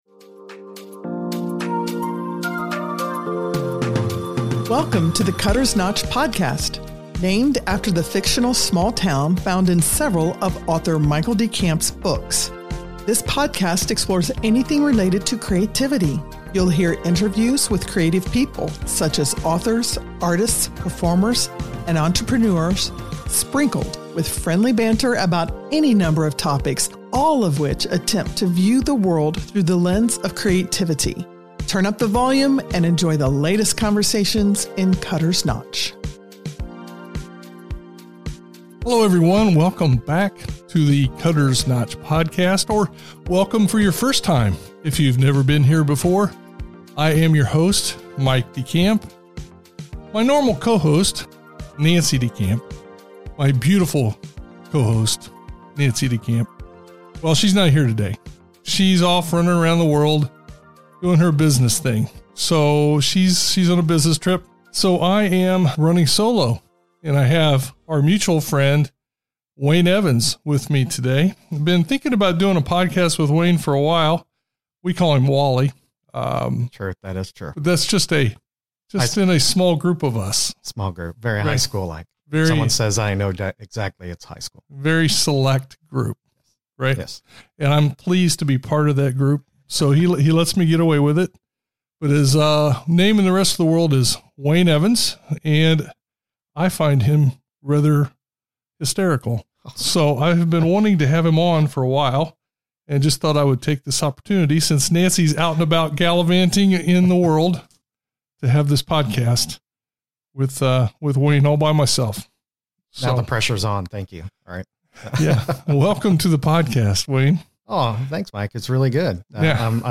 visits the Cutters Notch Studio to talk Stephen King, horror & Sci-Fi movies, travel tips, and jokes...lots of jokes.